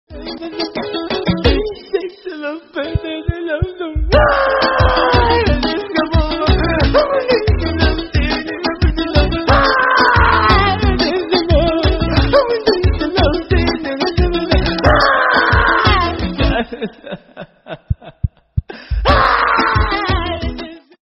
crying potato Meme Sound Effect